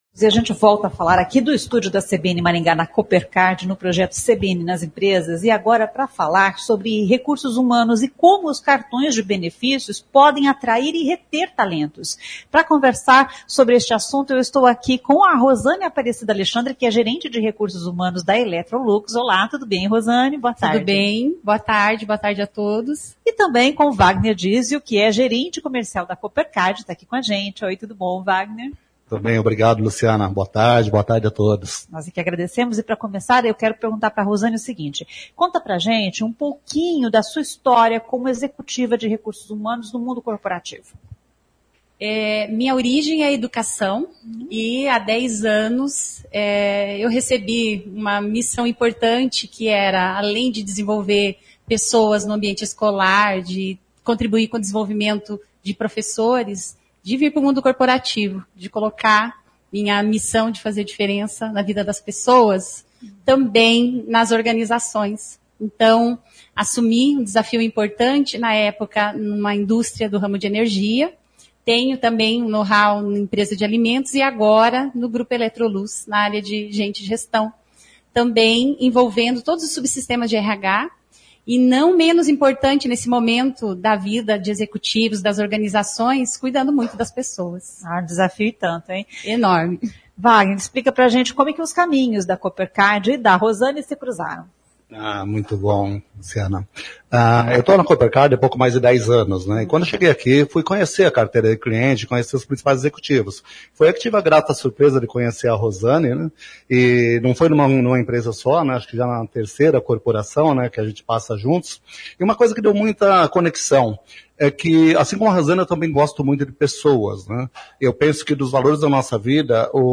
Os entrevistados explicam que benefícios e incentivos são diferenciais para atrair e reter talentos nas empresas.